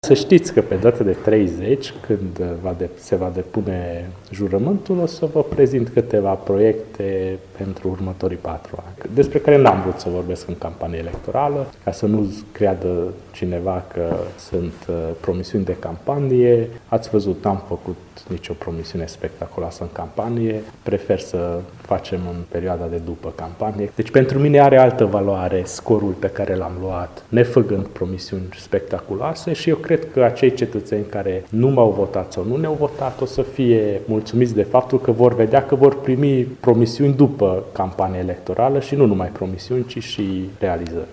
Primarul municipiului Sfântu Gheorghe, Antal Arpad, reales în funcţie pentru a treia oară pe lista UDMR, a anunţat joi că îşi va prezenta proiectele pentru următorii patru ani în cadrul şedinţei de constituire a viitorului Consiliu local, ce va avea loc pe data de 30 iunie: